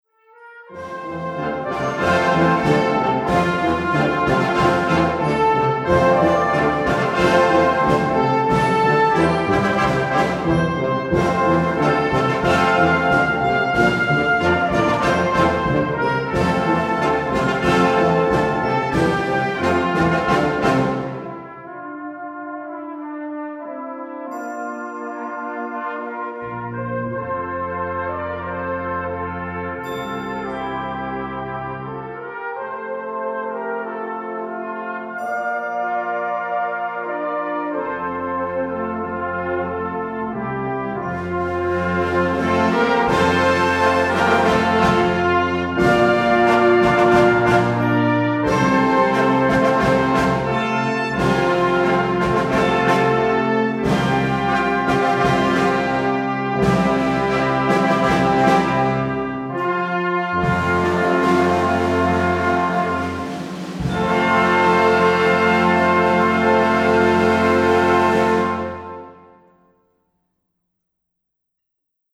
Australian Traditional.
2:30 Minuten Besetzung: Blasorchester PDF